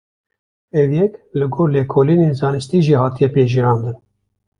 Uitgespreek as (IPA)
/ɡoːr/